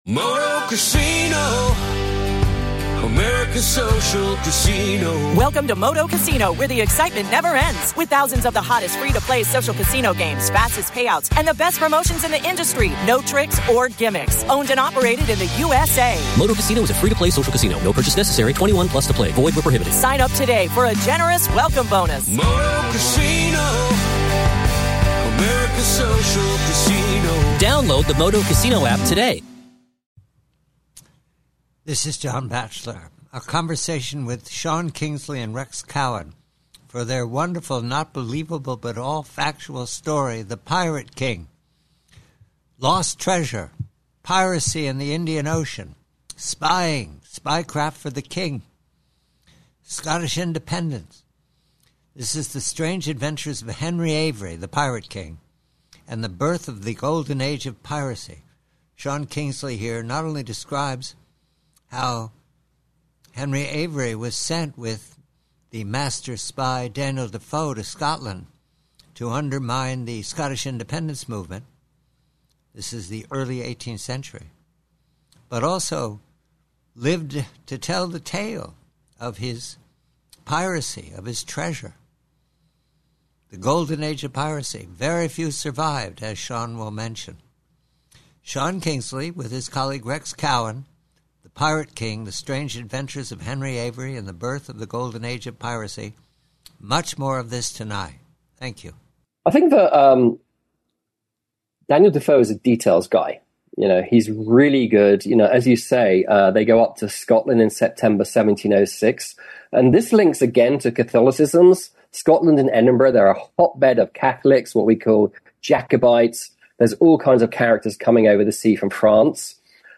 PREVIEW: PIRACY: DANIEL DEFOE: Conversation